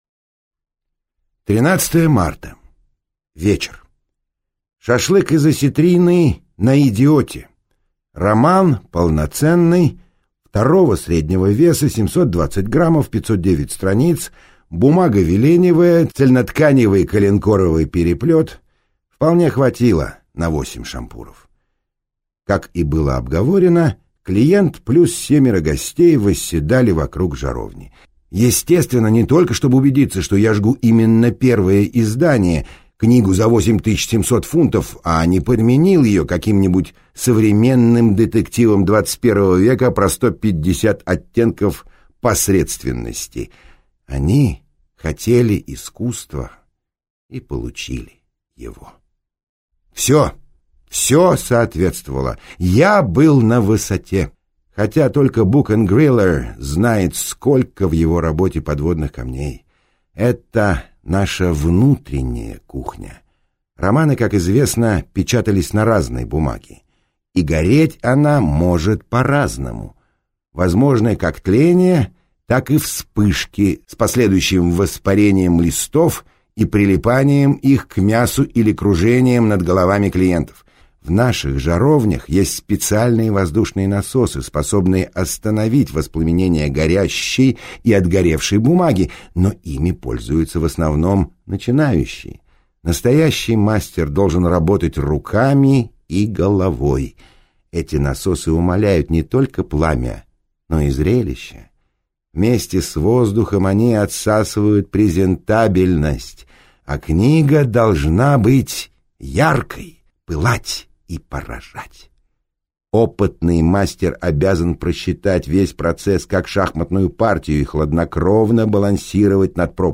Аудиокнига Манарага | Библиотека аудиокниг